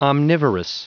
Prononciation du mot omnivorous en anglais (fichier audio)
Prononciation du mot : omnivorous